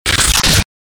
FX-1866-BREAKER
FX-1866-BREAKER.mp3